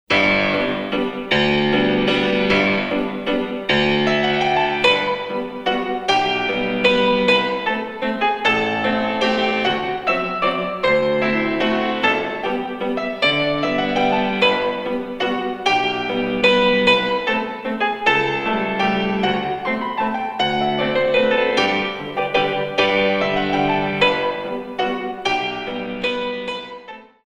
In 3
64 Counts